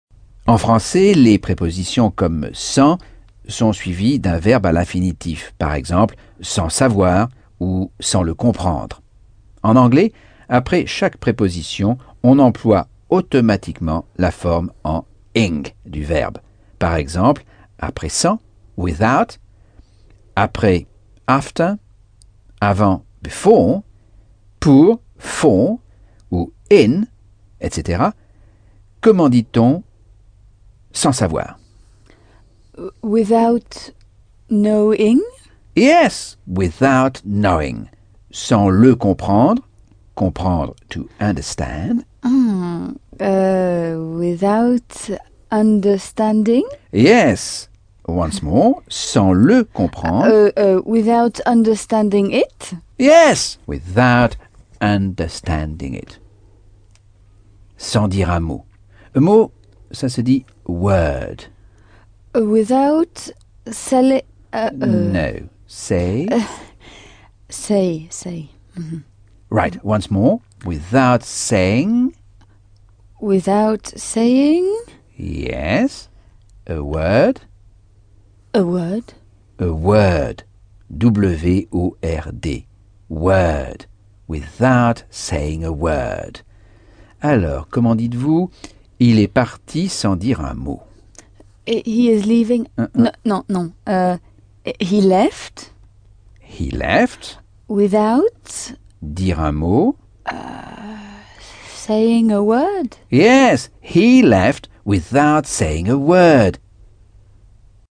Leçon 7 - Cours audio Anglais par Michel Thomas - Chapitre 10